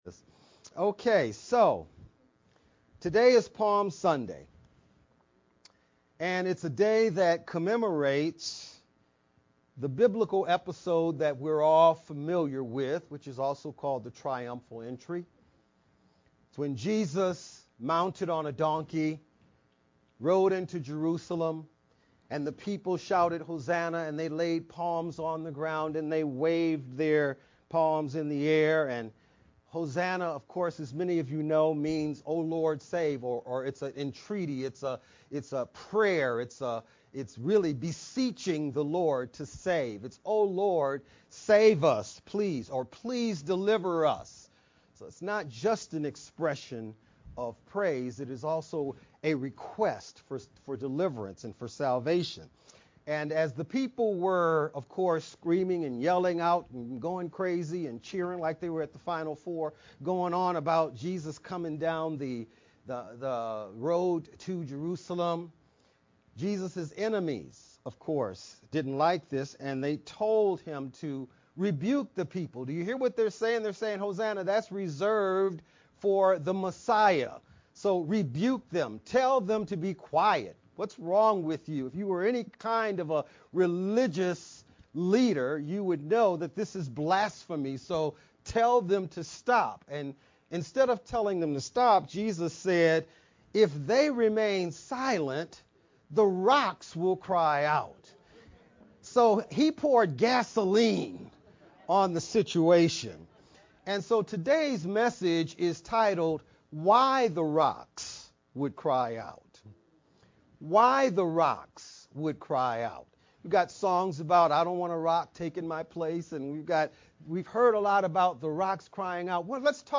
April-2nd-VBCC-edited-sermon-only-Mp3-CD.mp3